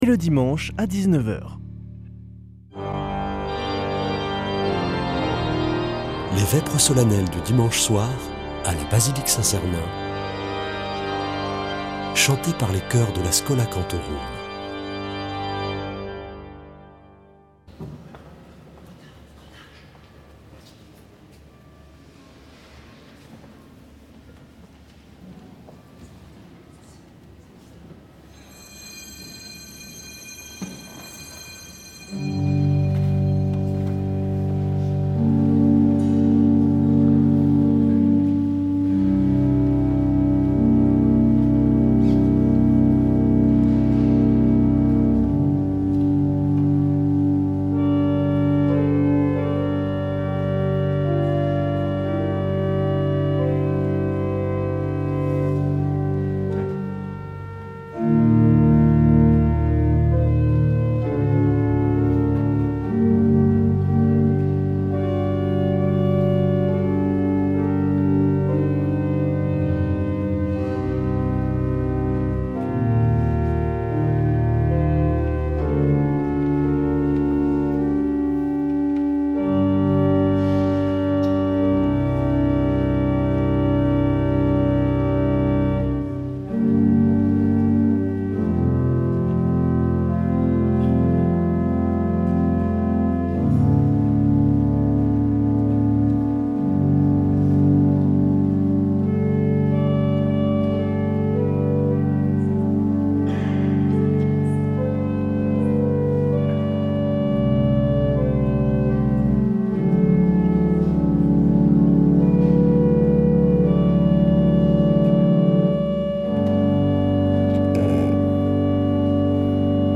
Vêpres de Saint Sernin du 24 déc.